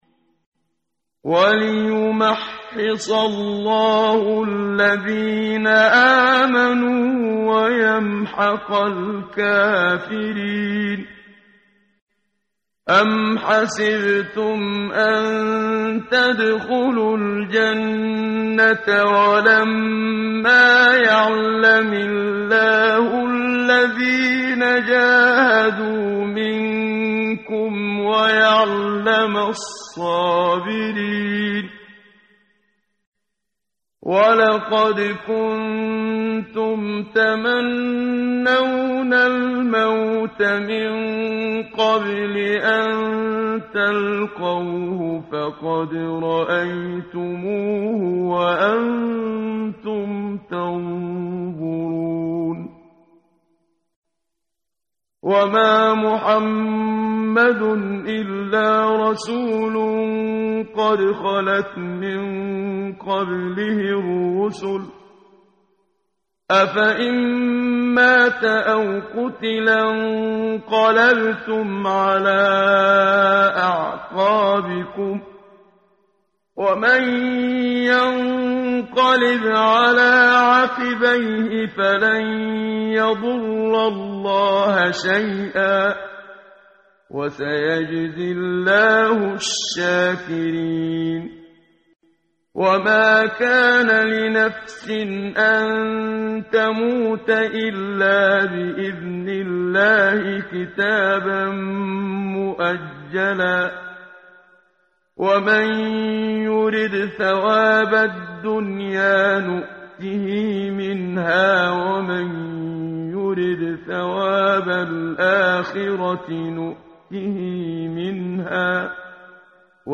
ترتیل صفحه 68( سوره مبارکه آل عمران (جزء چهارم) از سری مجموعه صفحه ای از نور با صدای استاد محمد صدیق منشاوی